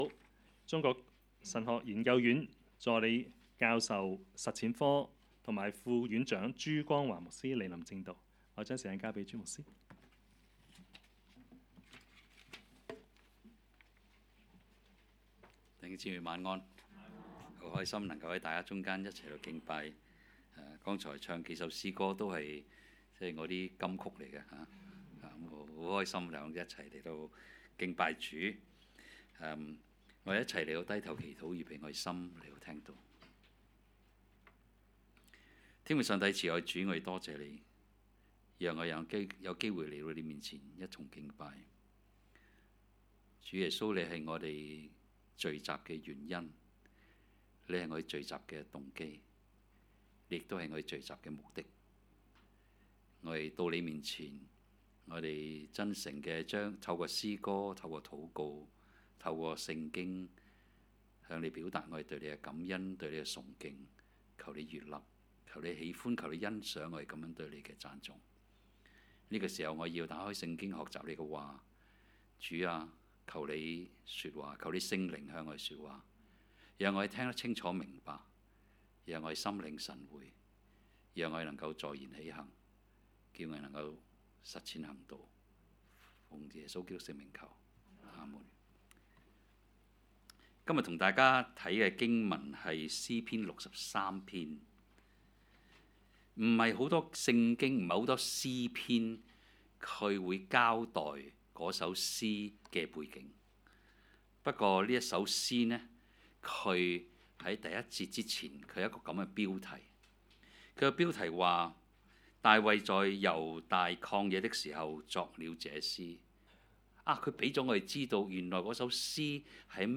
講道 ：困局中的想望